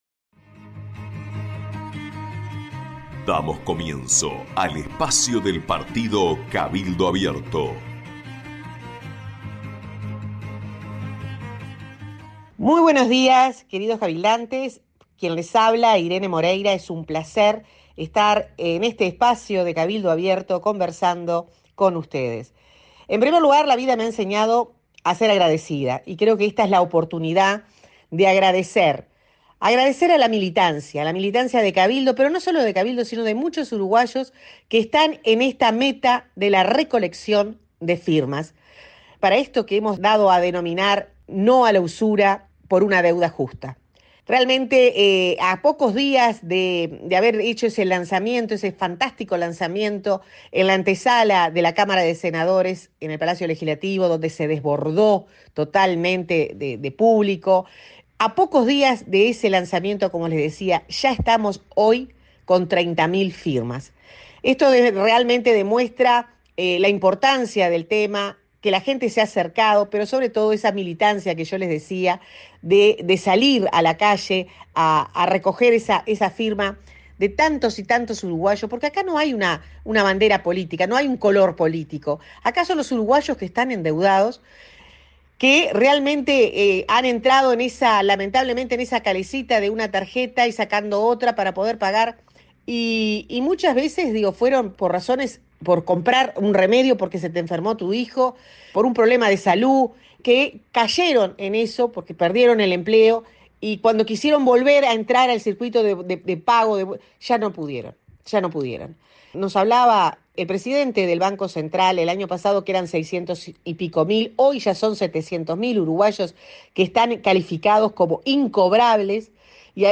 En la audición de este jueves por AM 770 Radio Oriental, la Senadora Irene Moreira comenzó refiriéndose a la campaña de recolección de firmas que viene llevándose a cabo en todo el país, y dijo: